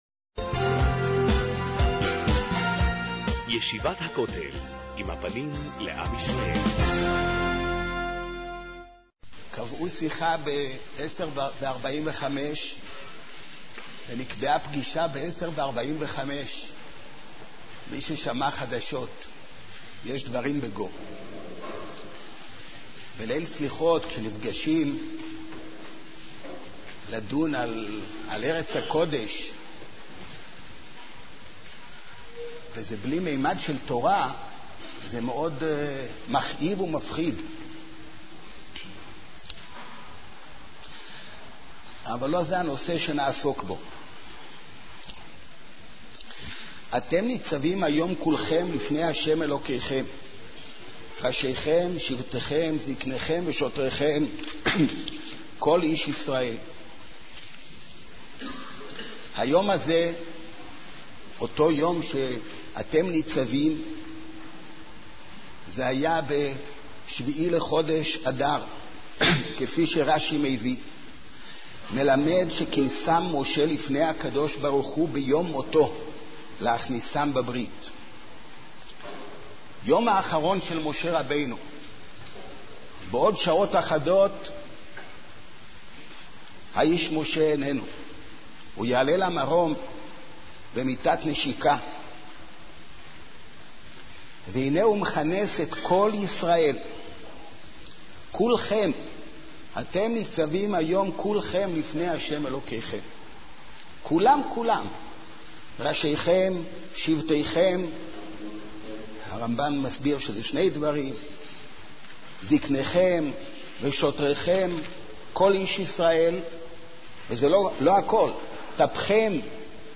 מאגר שיעורים תורני